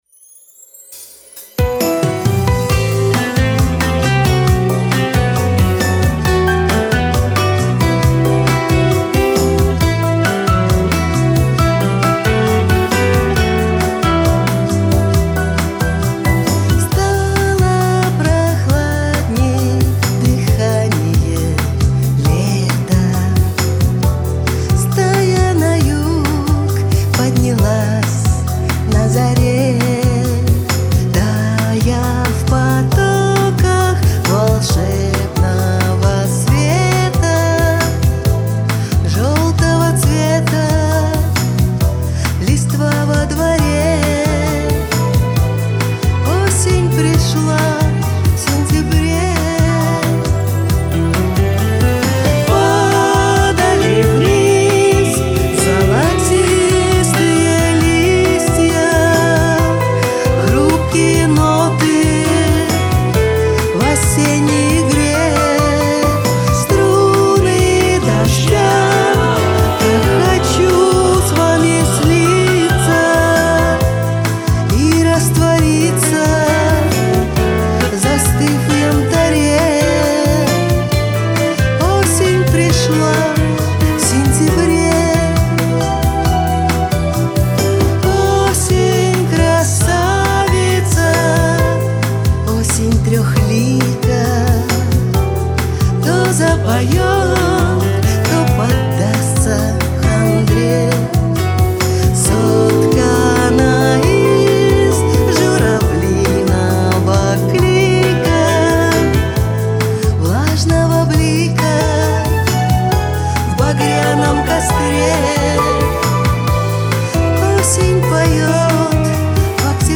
гитары, бэк-вокал
Аранжировка симпатичная, исполнение не плохо